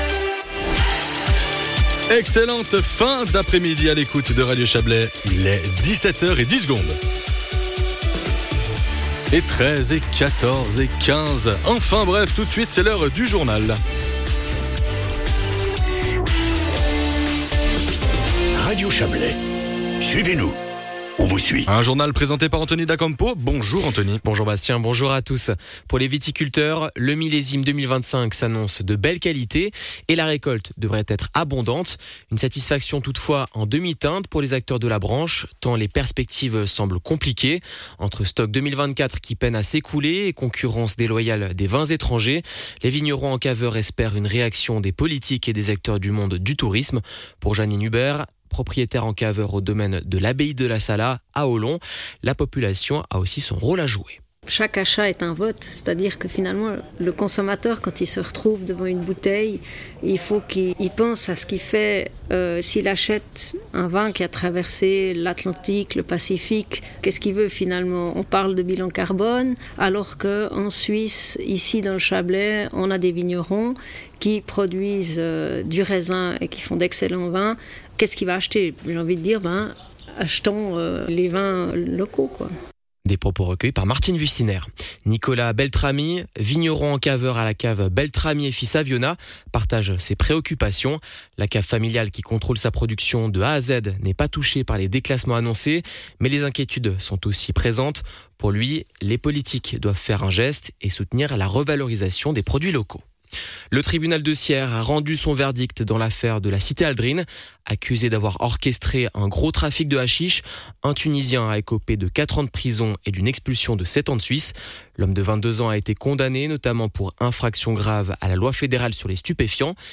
Les infos de 17h00 du 07.08.2025